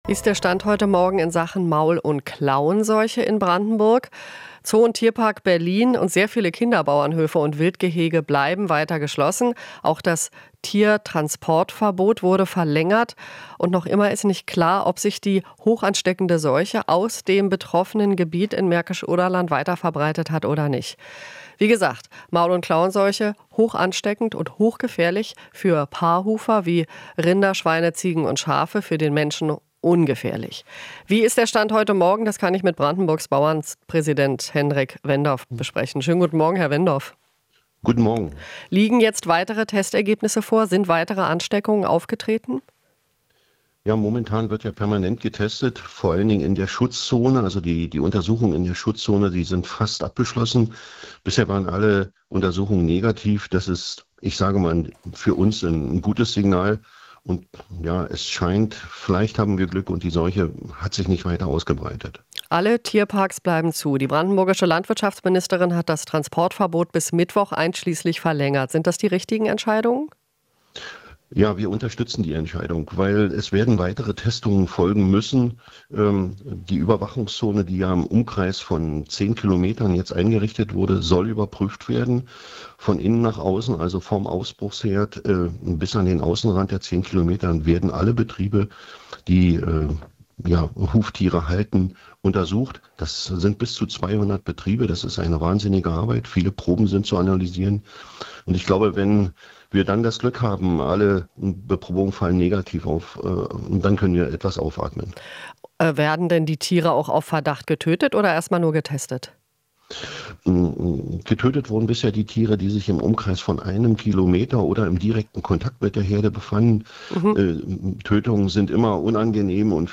Interview - MKS: Brandenburgs Bauern hoffen auf Normalisierung